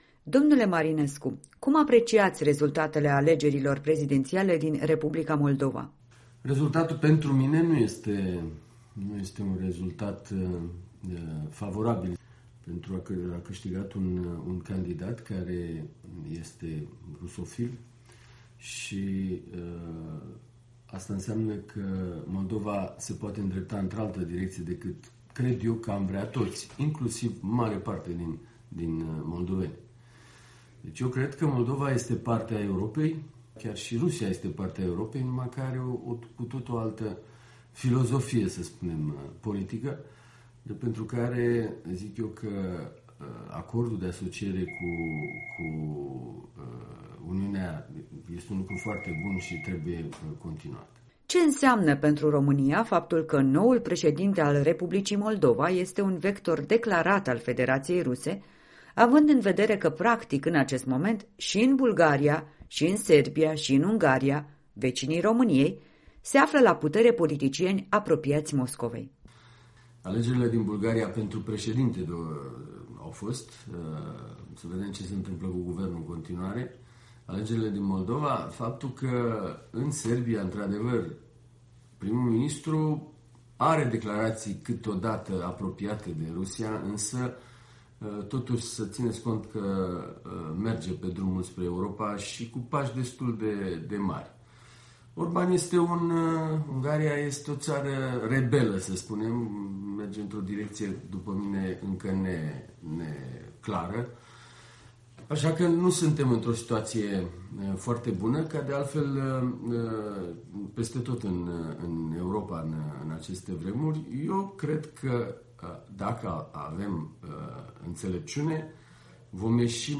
Un interviu cu vicepreședintele grupului PPE din Parlamentul European.